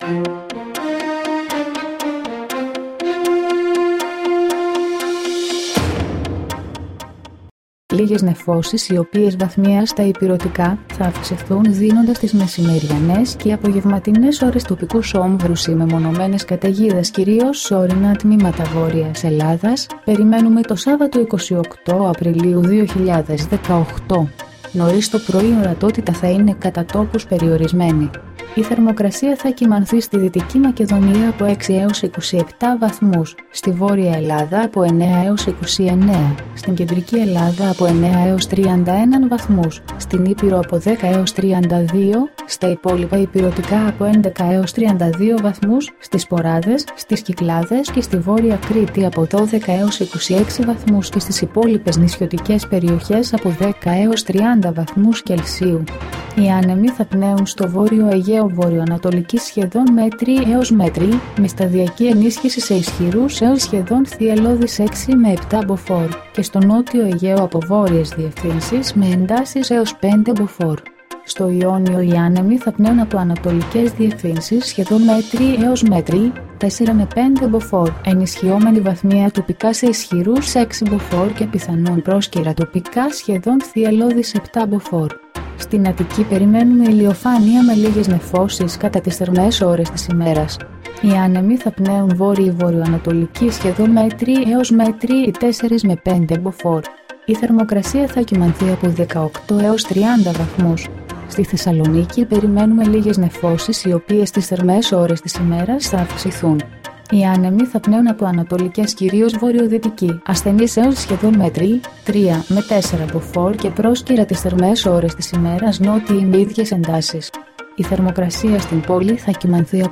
dailyforecastqqqqq.mp3